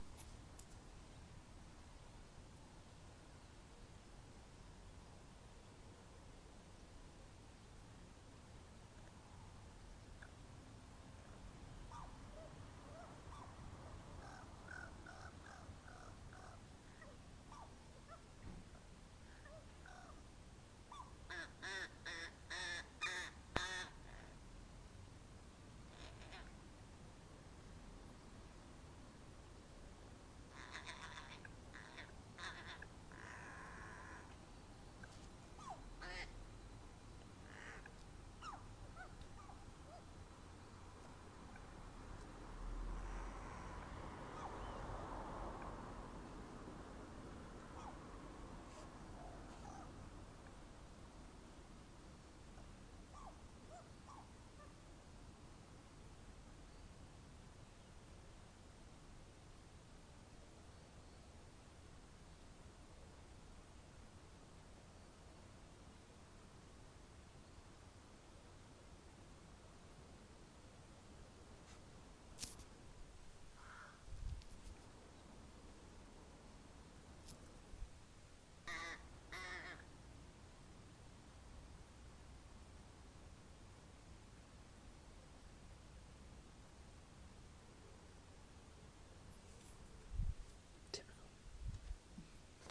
Rook mimicking a puppy dying